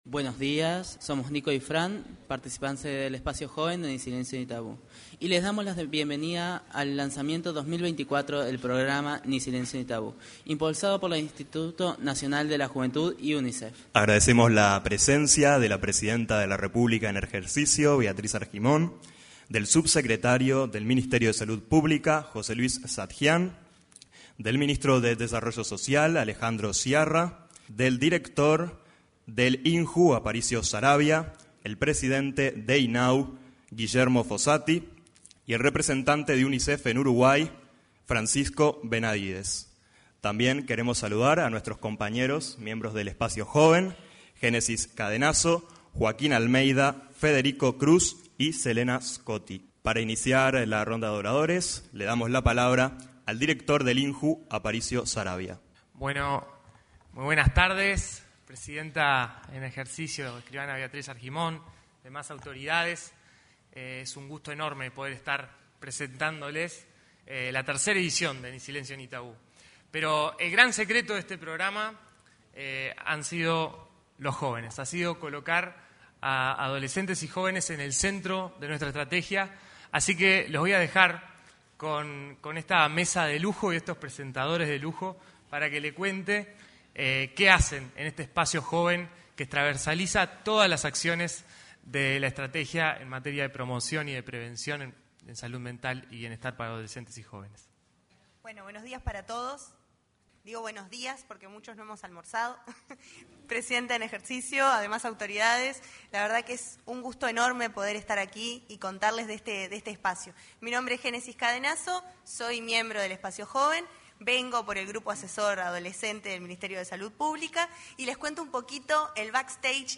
Este martes 29, se realizó, en al auditorio de la Torre Ejecutiva anexa, el lanzamiento de la edición 2024 del programa Ni Silencio Ni Tabú.